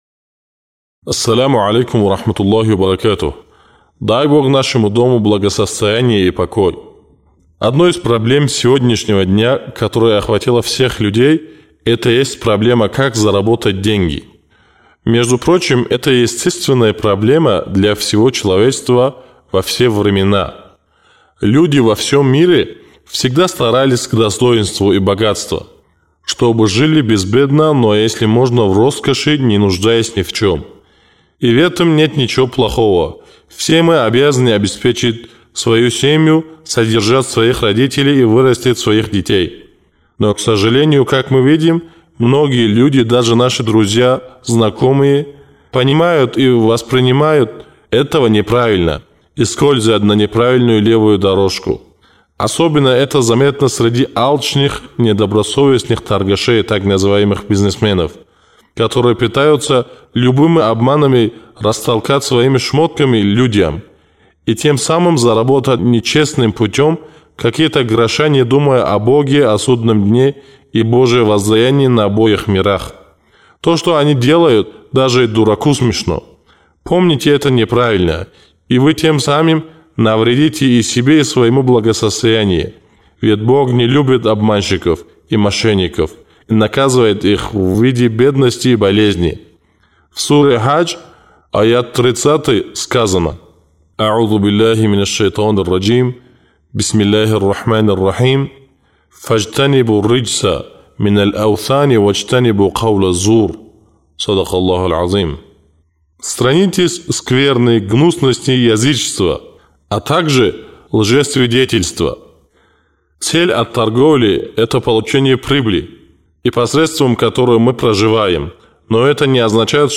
Может ли богатство принести нам счастье? Чтобы найти ответы на все эти вопросы, вы можете прослушать первую речь – «Богатство».